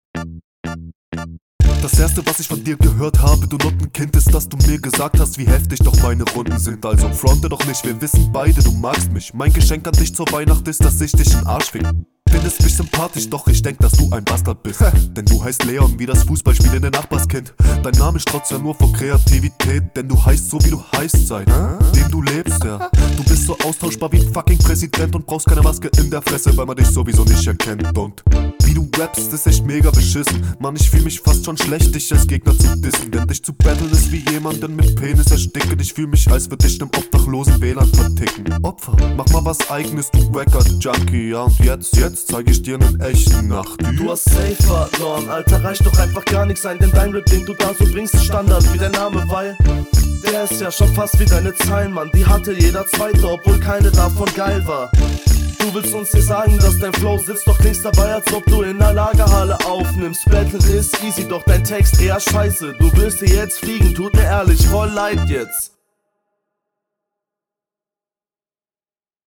Beat ist nicht meins.